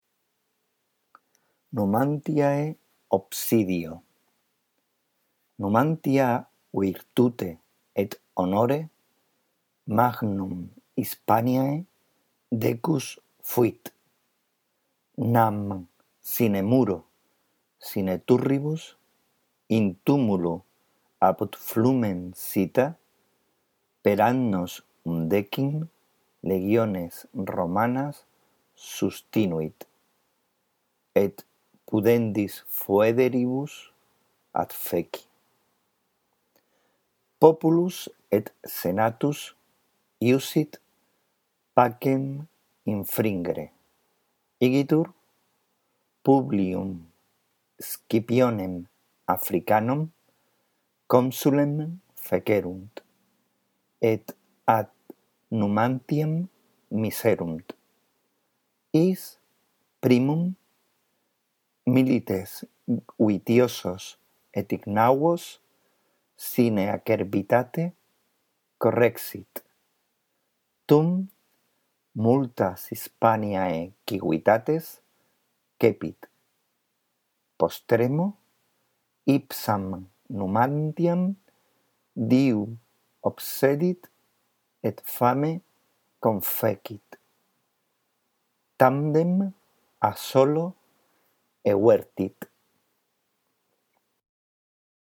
La audición de este archivo contribuirá a que mejores tu lectura del latín